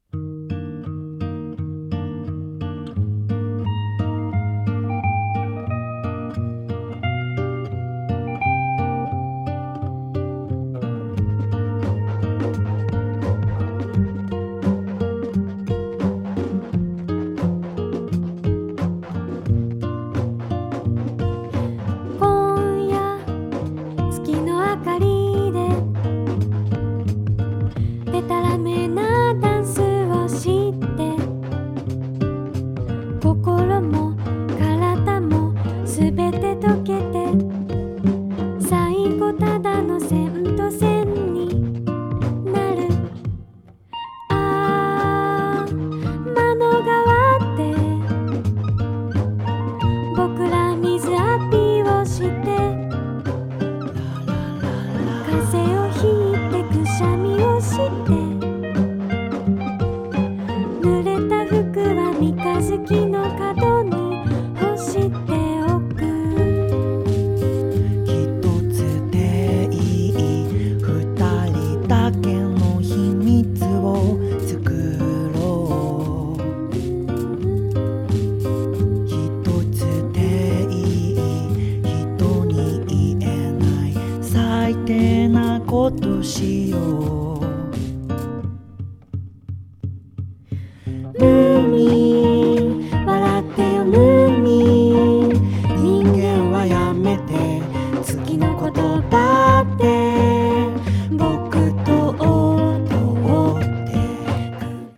サイケデリックに微睡む美しいメロディが◎！
温もりに満ちた歌を、親密に幻想的に聴かせてくれますよ！